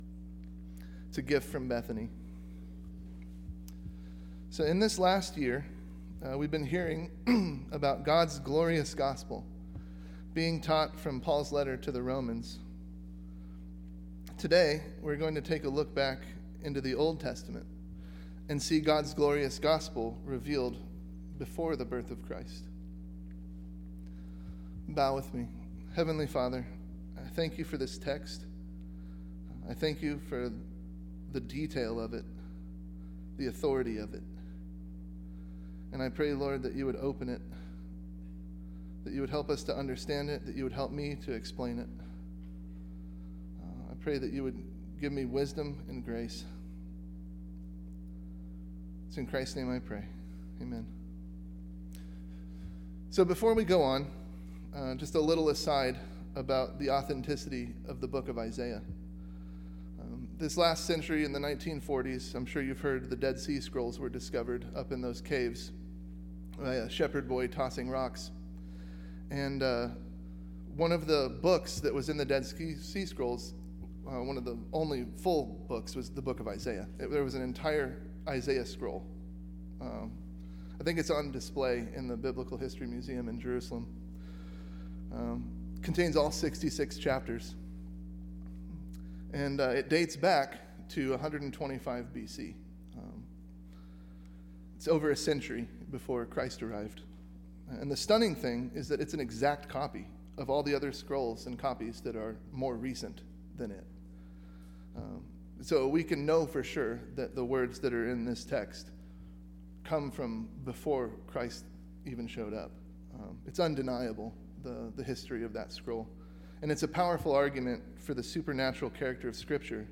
Passage: Isaiah 53:1-12 Service Type: Sunday AM